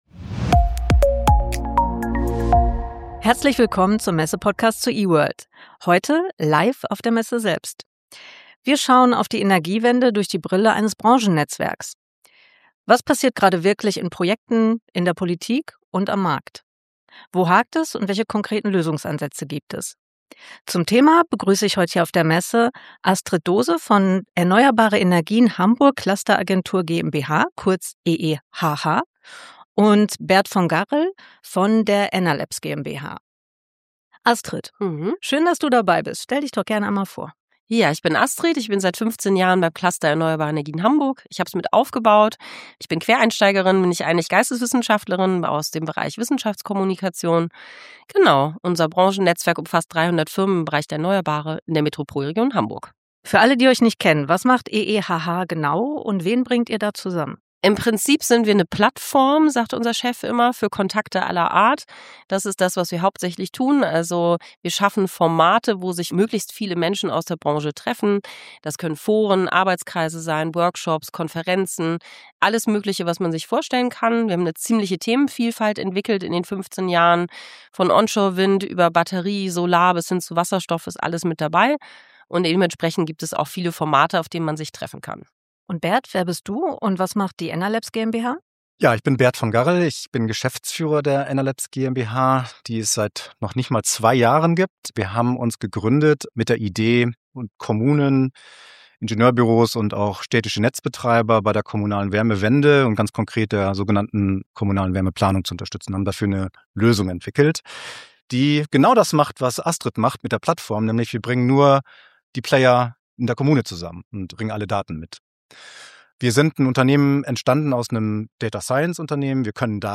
In dieser Episode nehmen wir euch mit auf die Messe E-World und werfen gemeinsam mit spannenden Gästen einen Blick durch die Brille eines Branchennetzwerks auf die Energiewende.